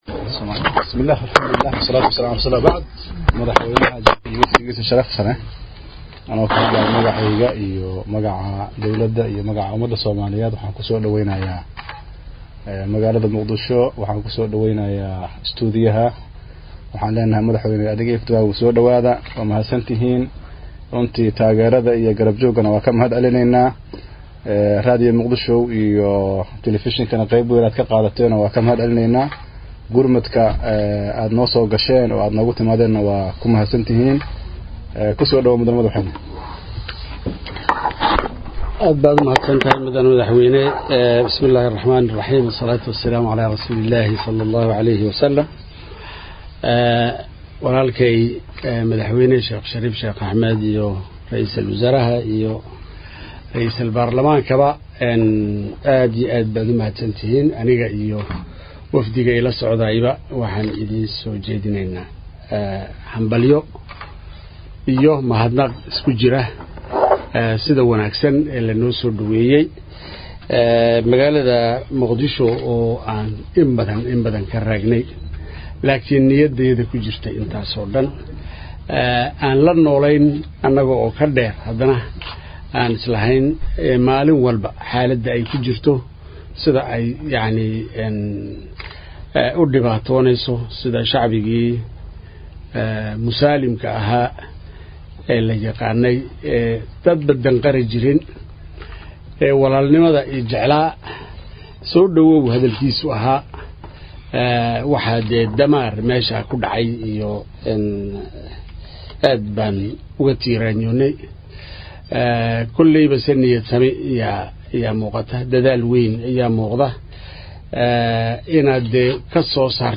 Dhagayso Kalmadii Madaxwayne Geele
Mudane Imaaciil Cumar Geelle oo shir jaraa’id ku qabtay safaaradda Jabuuti ay ku leedahay magaalada Muqdisho ayaa sheegay in wax indhihiisa aanay qaban karin uu ku soo arkay xerada Badbaado iyo isbitaalka Banaadir, isagoona baaq u diray beesha caalamka iyo dalalka Islaamka.